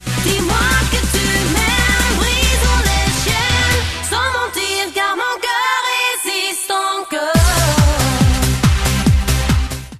belgijska wokalistka